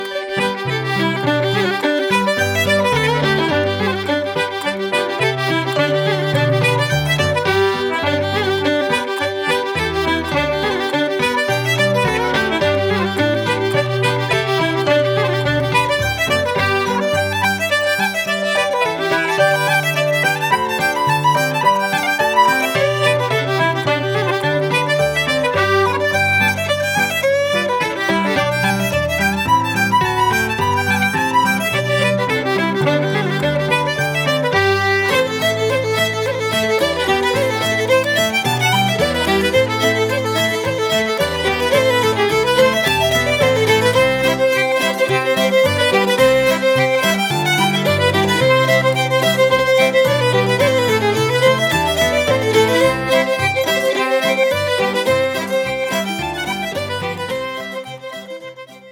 Fiddle and concertina from County Meath.